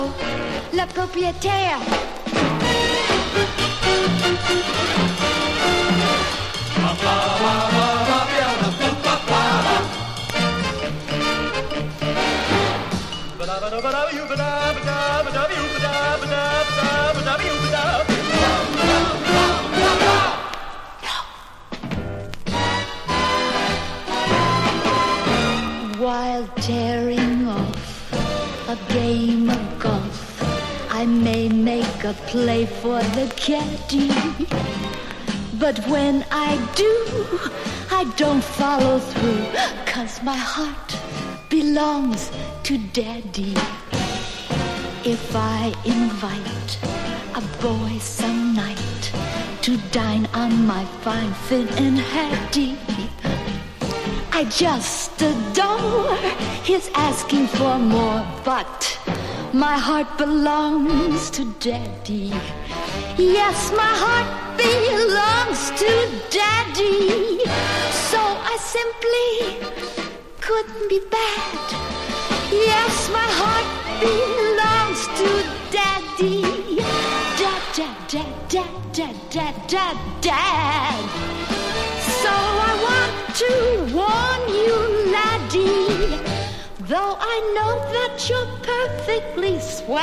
VOCAL & POPS